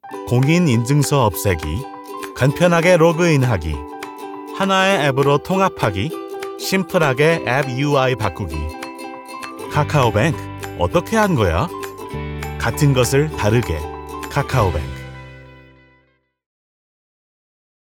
native Korean female voice
Nagranie lektorskie